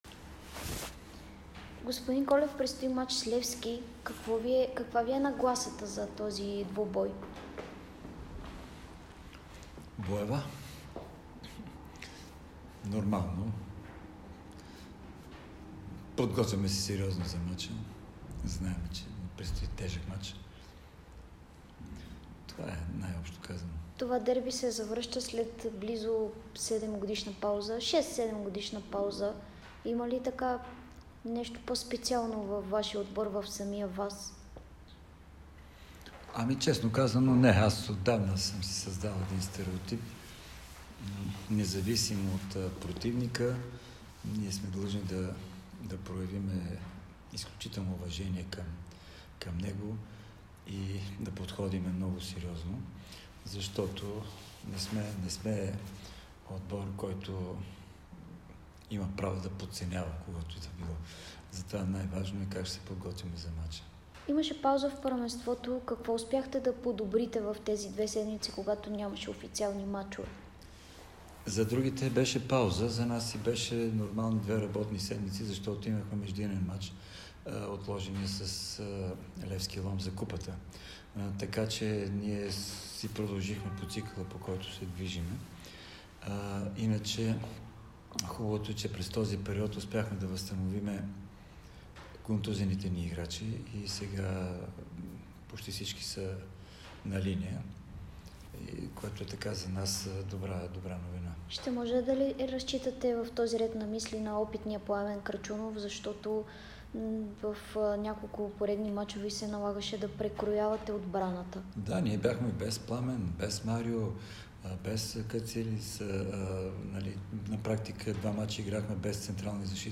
Старши треньорът на Локомотив София Иван Колев говори пред Дарик и dsport преди двубоя с Левски от 11-ия кръг на efbet Лига.